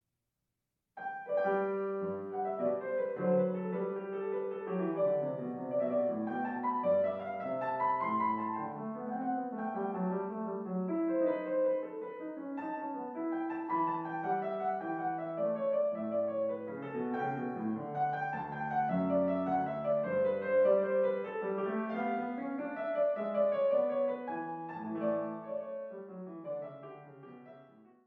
Classical Music Piano